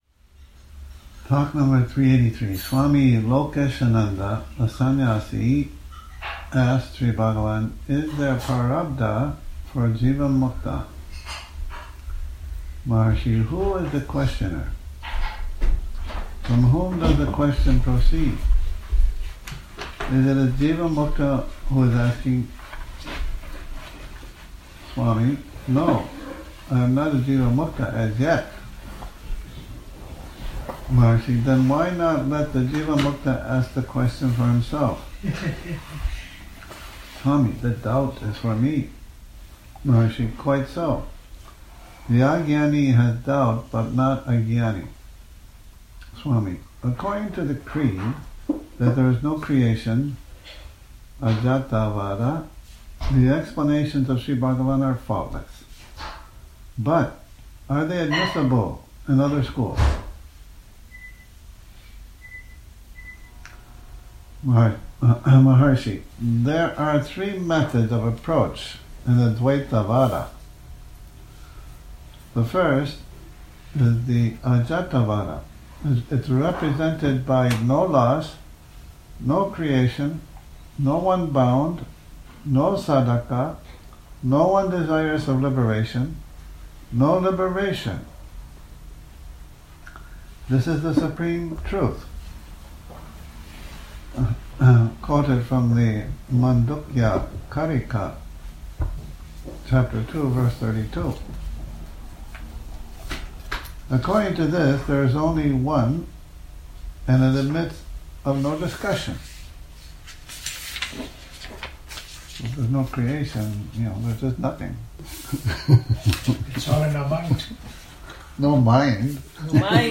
Morning Reading, 30 Sep 2019